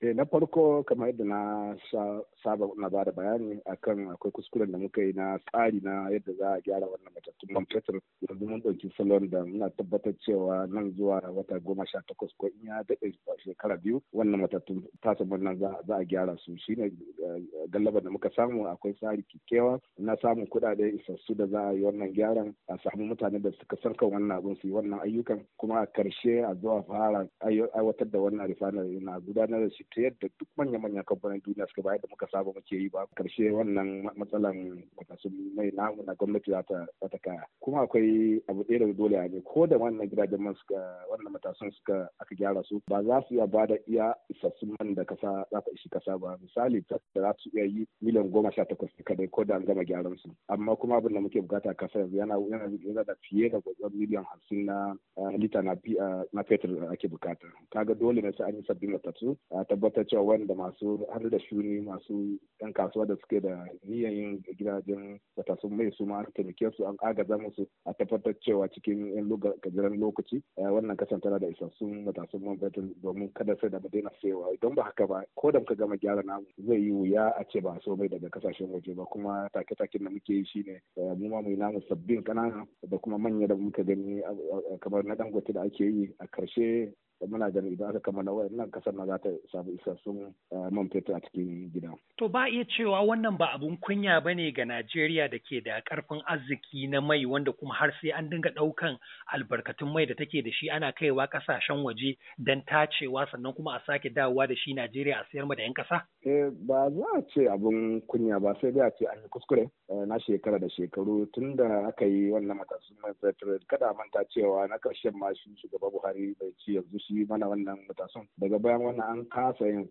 Yayin wata hira ta musamman da ya yi da Muryar Amurka, Kyari ya ce Najeriya ta hada kai da kasar Rasha a kokarin da take yi na ganin ta gudanar da gyaran wadannan matatun man.